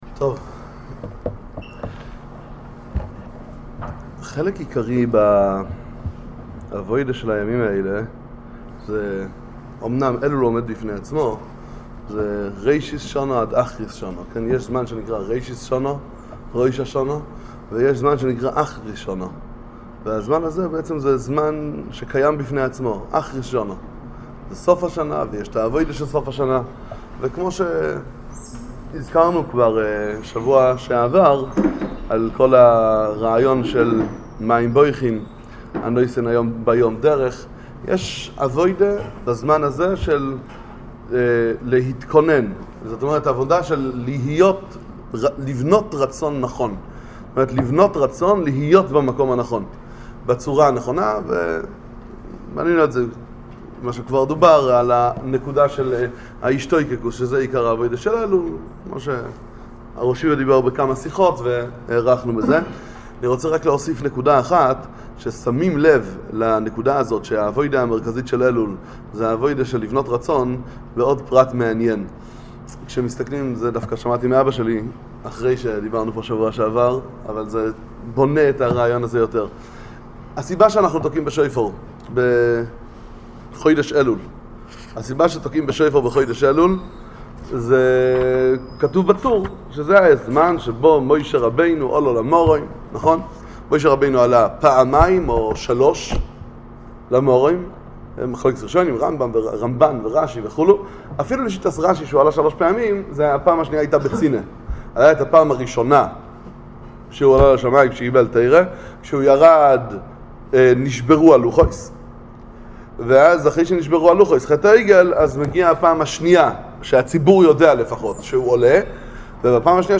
שיעורי תורה ושיחות חיזוק לחודש אלול